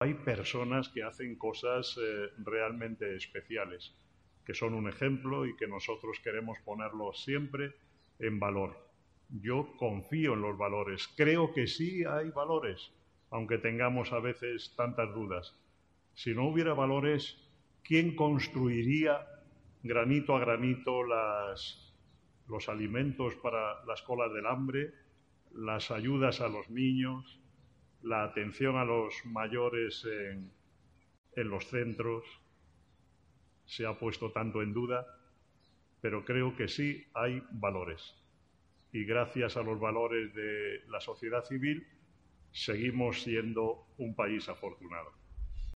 en la emisión de un video integrado en la gala.